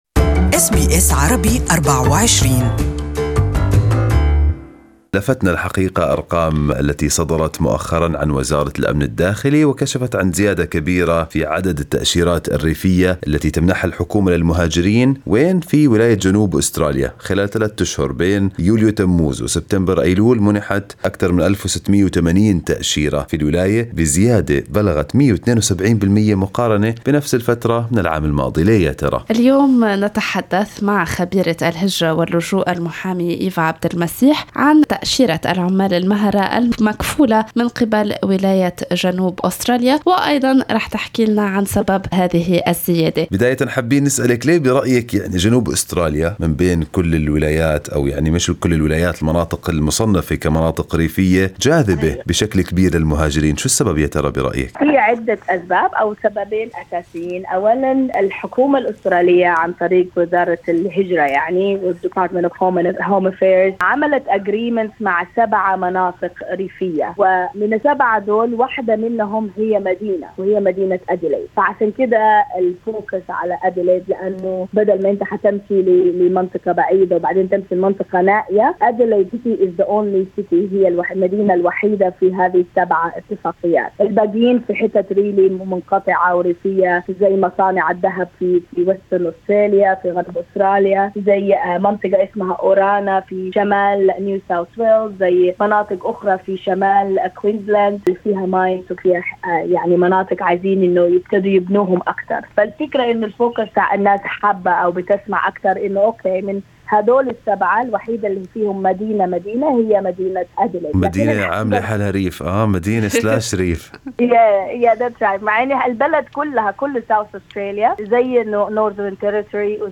Listen to the interview with migrant agent in Arabic above